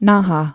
(nähä)